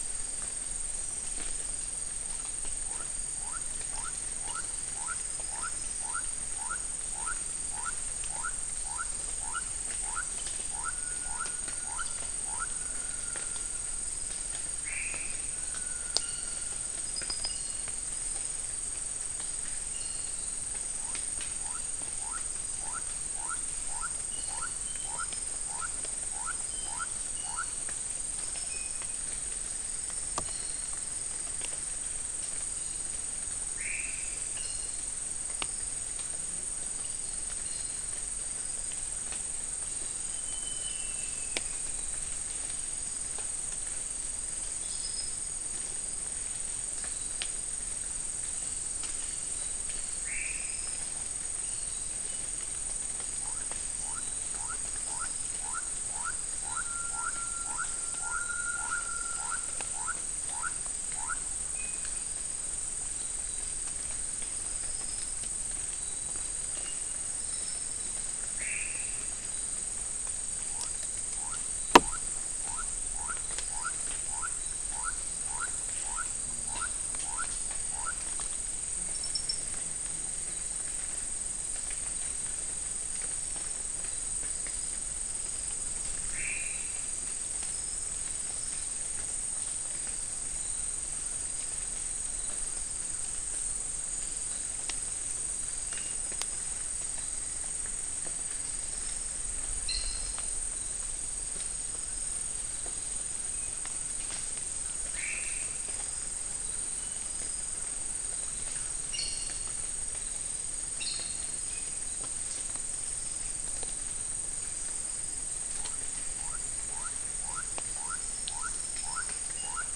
Non-specimen recording: Soundscape
Location: South America: Guyana: Turtle Mountain: 1
Recorder: SM3